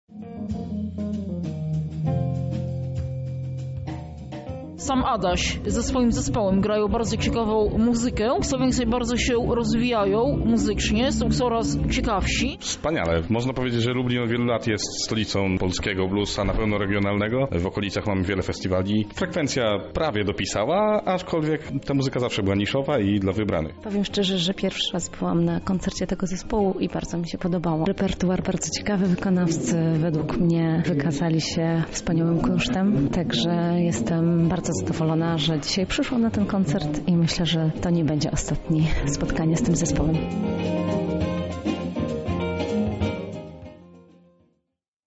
Muzyk improwizowaną usłyszeliśmy podczas koncertu THE COOLEST JAM SESSION - Radio Centrum
Wydarzenie polega na tym, że jeden z muzyków podaje przykładowy temat. Muzyczna myśl jest następnie rozwijana i przekształcana przez pozostałych instrumentalistów.
Lublin-Blues-Session-1.mp3